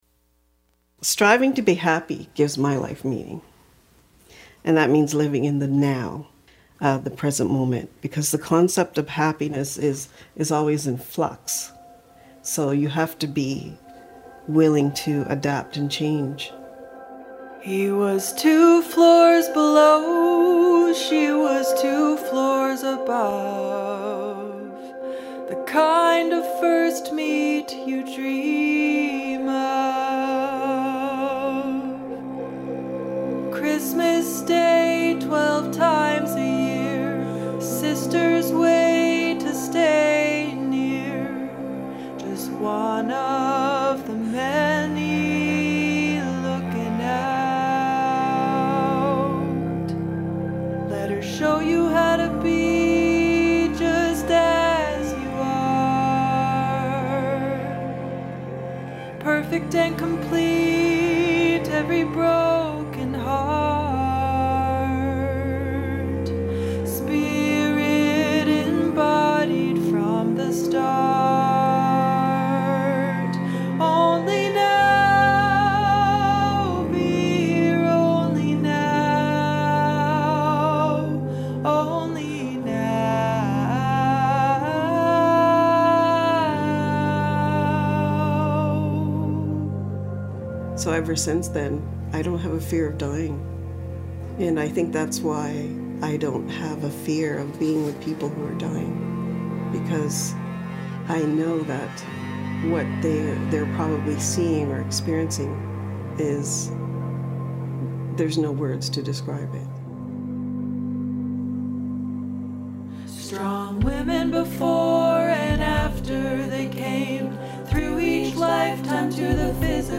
You’re listening to Cinesthesia, produced live in the studio at Trent Radio 92.7 CFFF FM in beautiful (Nogojiwanong) Peterborough, Ontario, every Thursday at 5 PM.